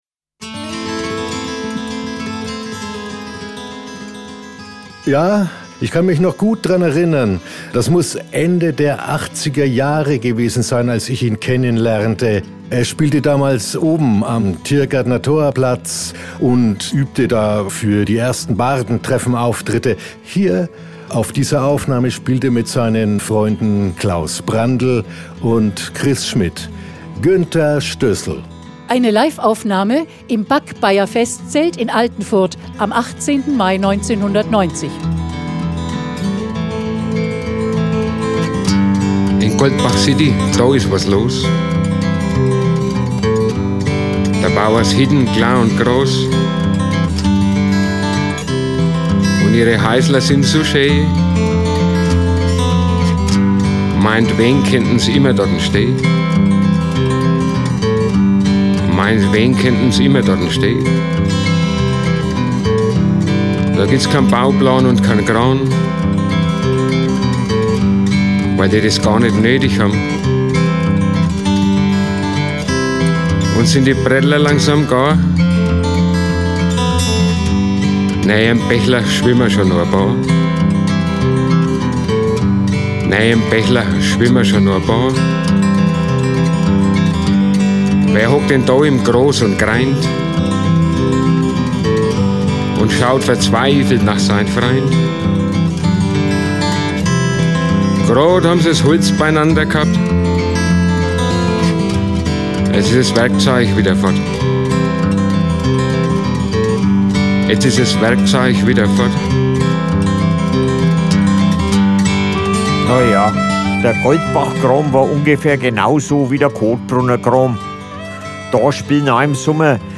Diese kurzen Hörspiele (max. 10 Minuten) sind von Mitgliedern des Blumenordens, die in den zwanziger Jahren dieses Jahrhunderts leben oder noch gelebt haben, geschrieben und gesprochen, gespielt oder musikalisch vorgetragen.